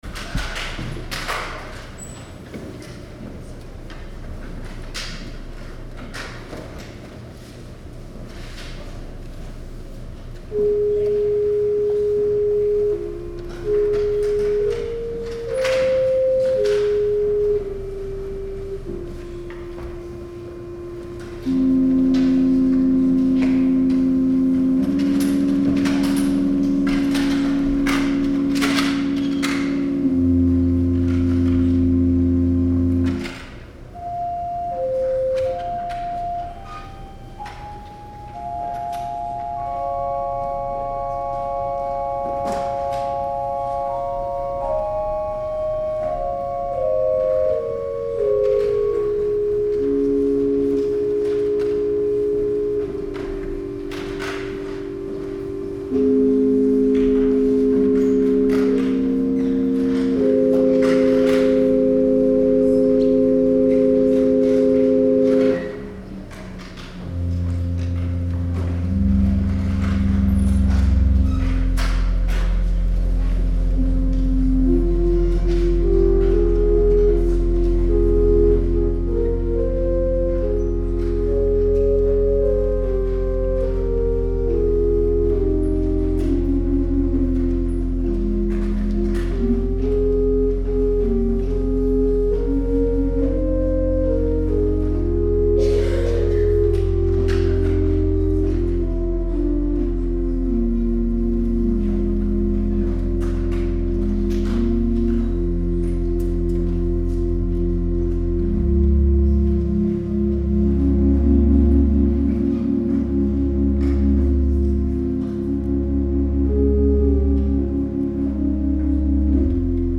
Music from November 17, 2019 Sunday Service